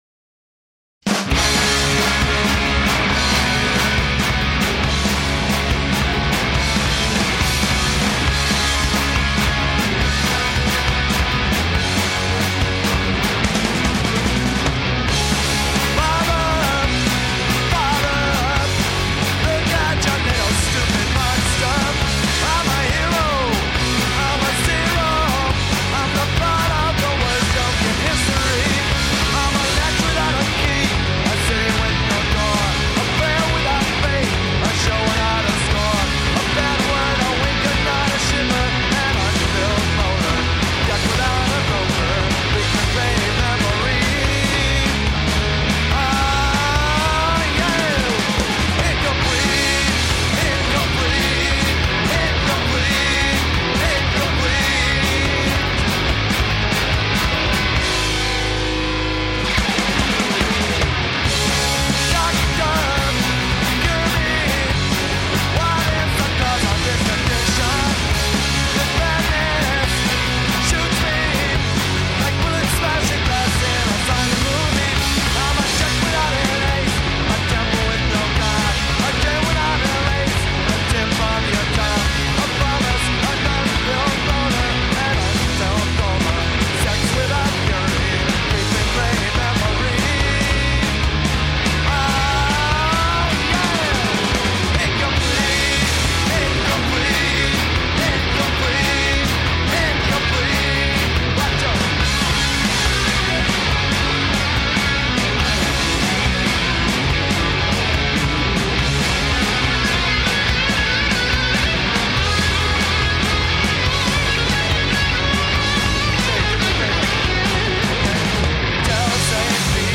Tracks 1 and 4: session on BBC Radio 1 on February 21, 1995